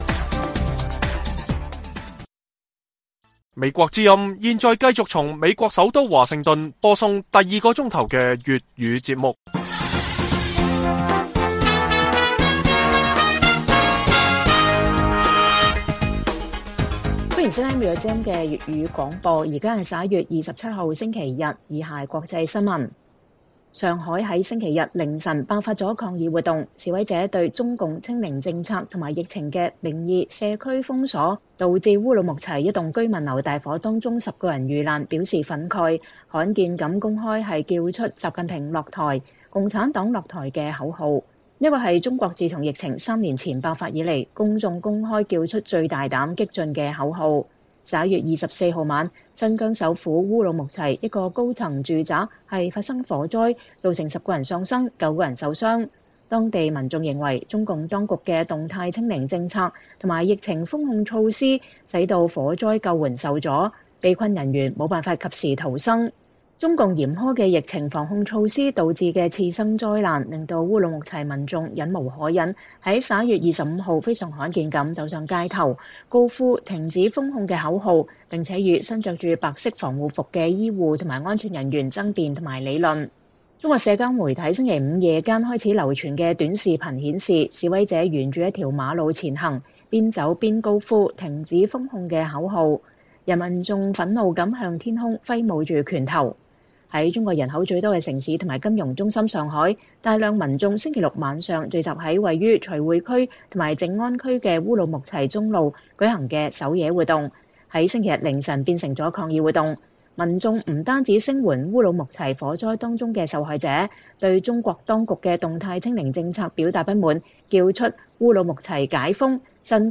粵語新聞 晚上10-11點: 中國的民怨和民聲：從“停止封控” 到“習近平下台””